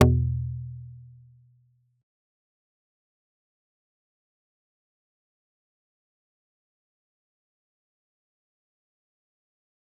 G_Kalimba-E2-pp.wav